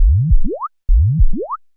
K-7 Whoop Zap.wav